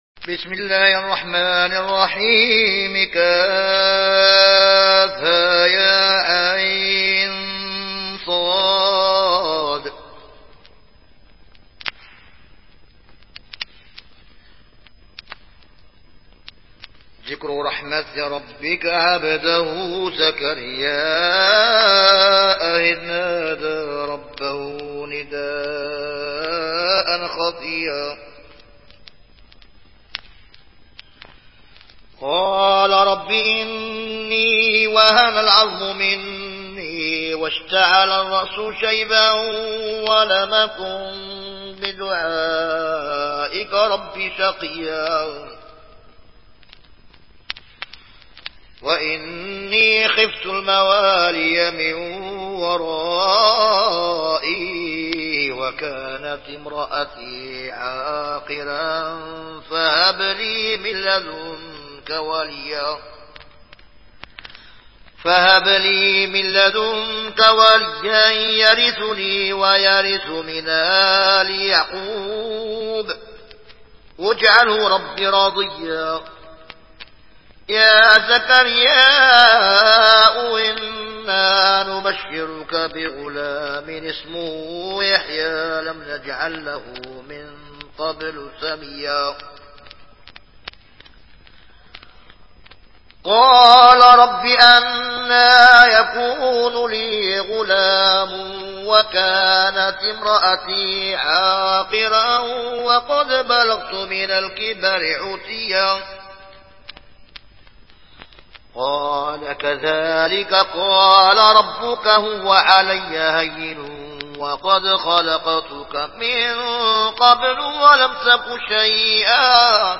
Riwayat Warch an Nafi